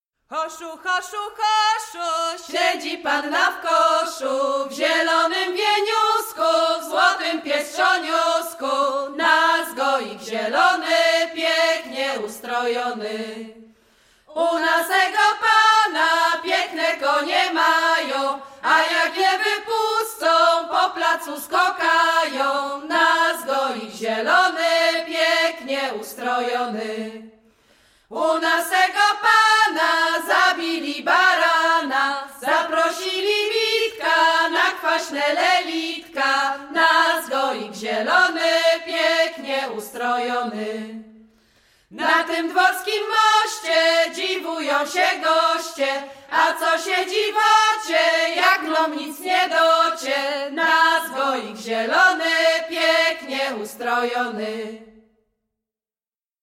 Śląsk Opolski
Marzanna
gaik kolędowanie wiosenne maik wiosenne wiosna marzanna goik